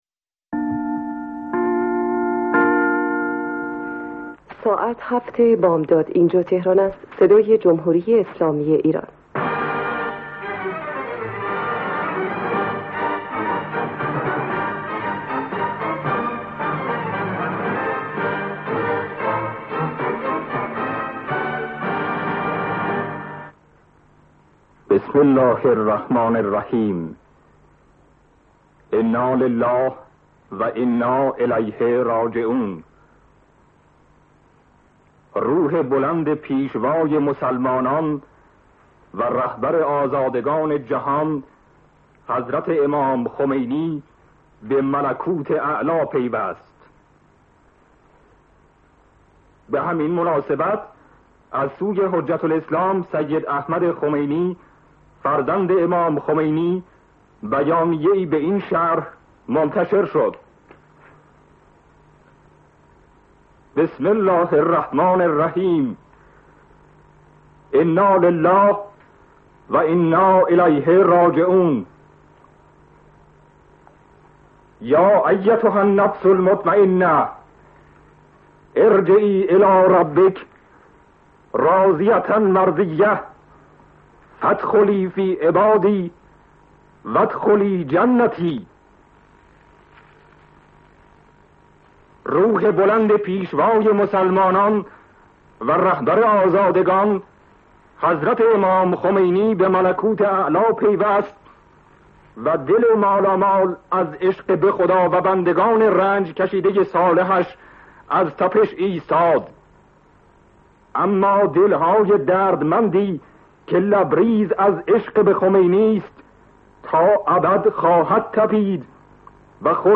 :Sham: خبر رحلت امام از اخبار شبکه یک توسط آقای حیاتی :Sham:
Hayati_Khabar Rehlat Emam.mp3